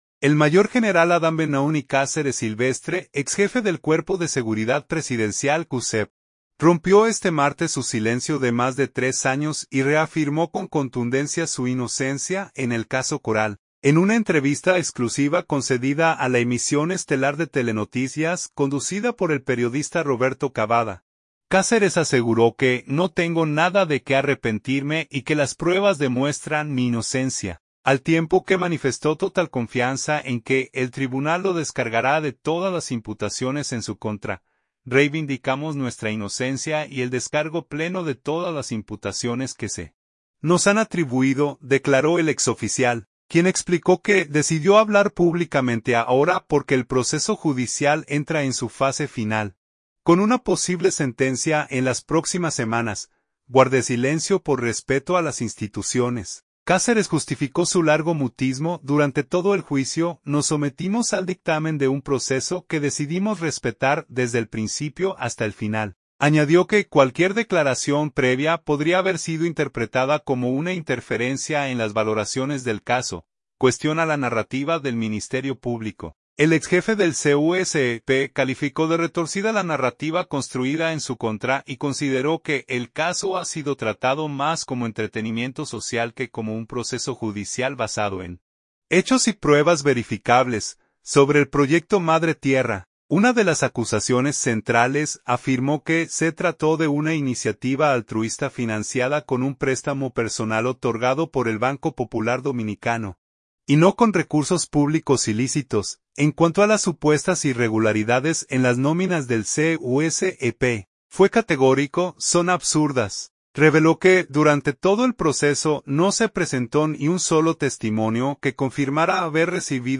En una entrevista exclusiva concedida a la Emisión Estelar de Telenoticias, conducida por el periodista Roberto Cavada, Cáceres aseguró que “no tengo nada de qué arrepentirme” y que “las pruebas demuestran mi inocencia”, al tiempo que manifestó total confianza en que el tribunal lo descargará de todas las imputaciones en su contra.